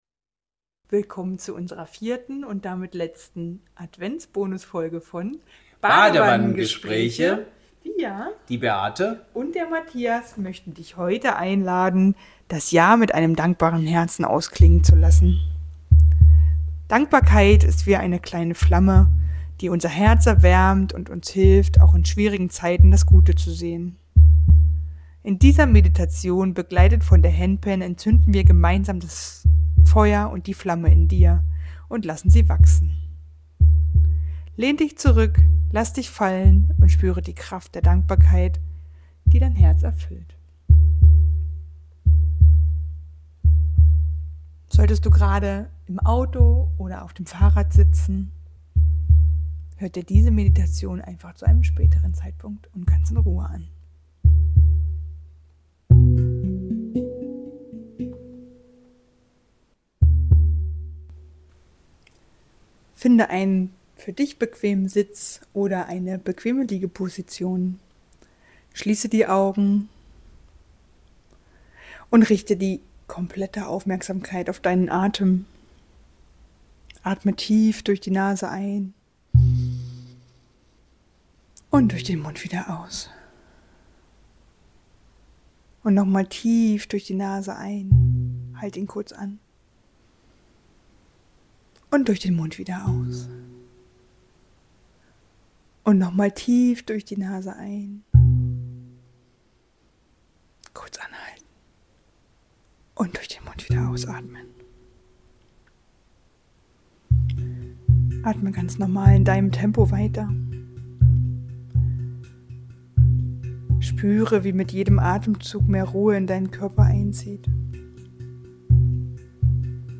Besinnliche Momente zur Weihnachtszeit